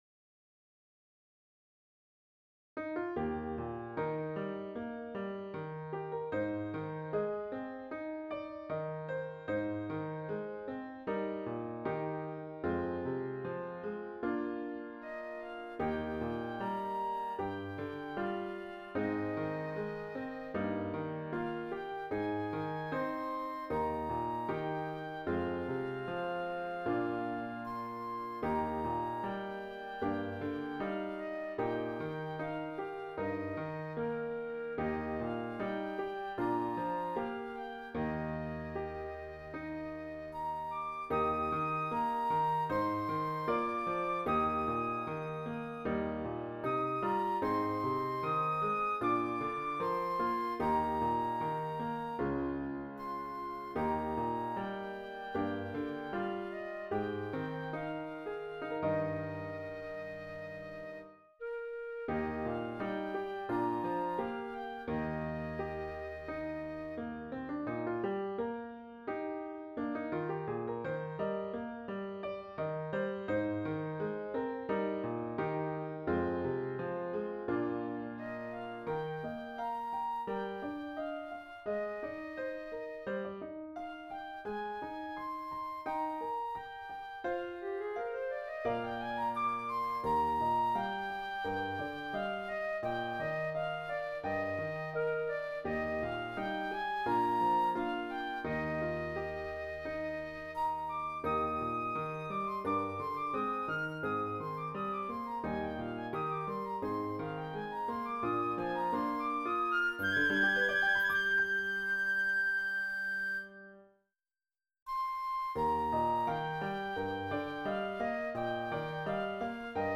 Intermediate Instrumental Solo with Piano Accompaniment.
Christian, Gospel, Sacred, Folk.
A Hymn arrangement
put to a flowing folk setting.